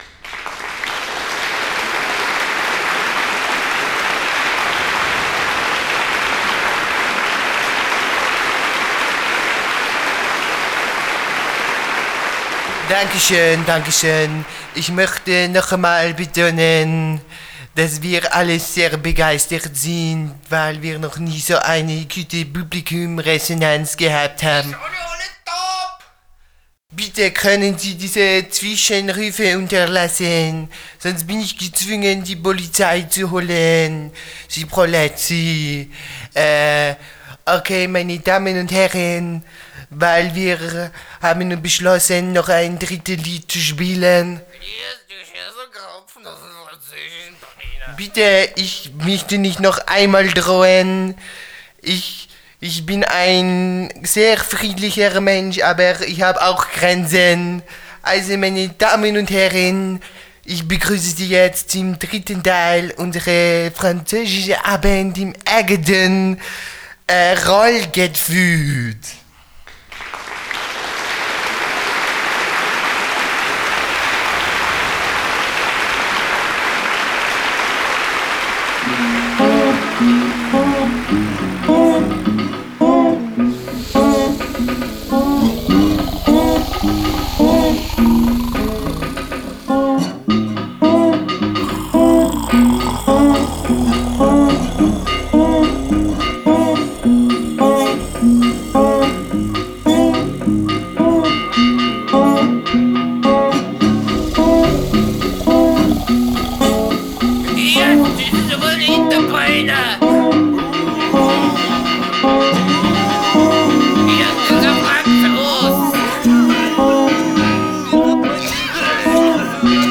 Genre: Freie Musik - Französische Avantgarde